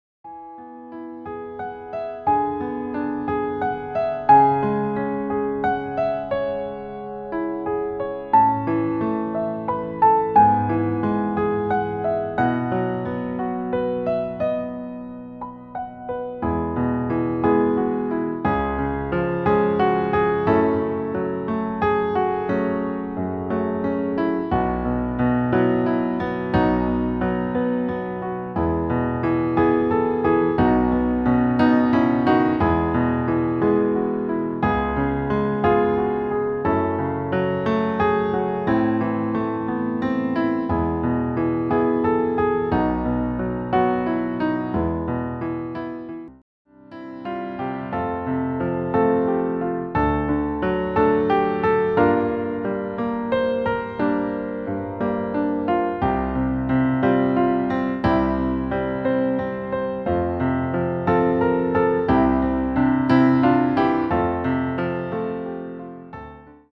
• Art: Flügelversion
• Das Instrumental beinhaltet keine Leadstimme